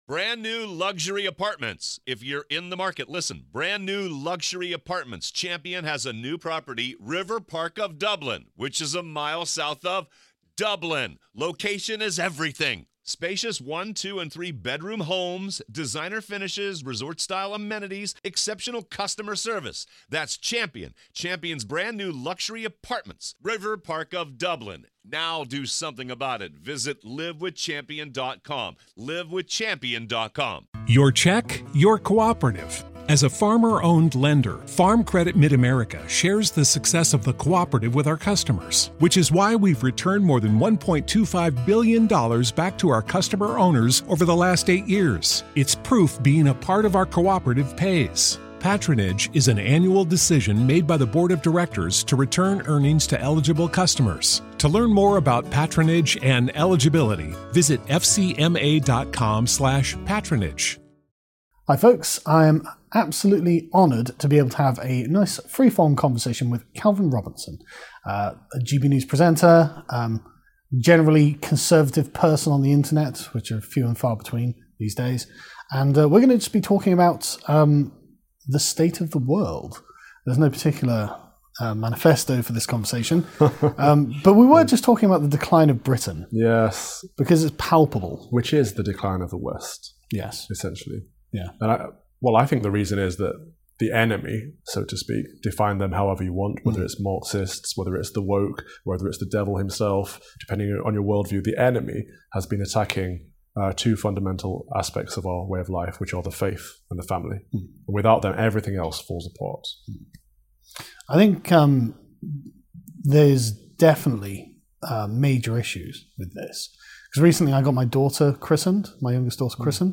Interview with Calvin Robinson